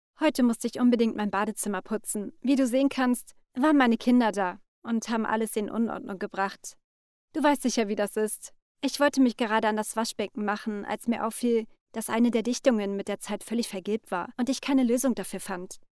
Falls genau Du eine junge und frische Stimme suchst, bist Du bei mir genau richtig.
Kein Dialekt
Sprechprobe: Industrie (Muttersprache):
Sample Natural.MP3